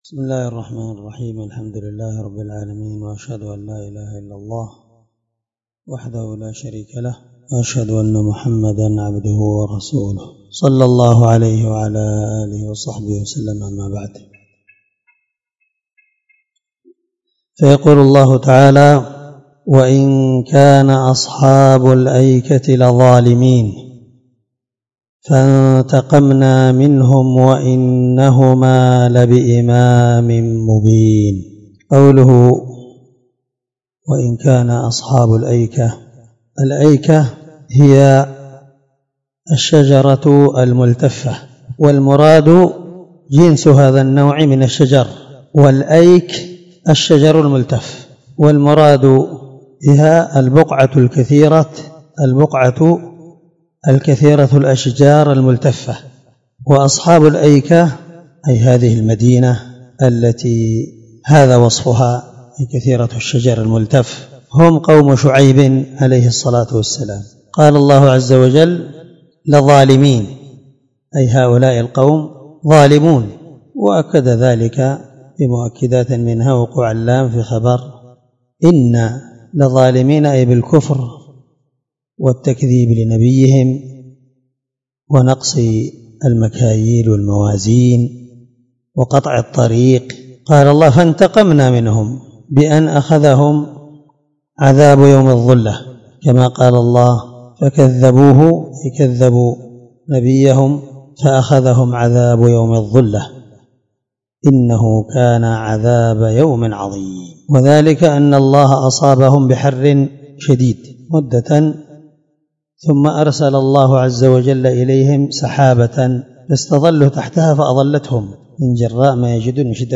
15سورة الحجر مع قراءة لتفسير السعدي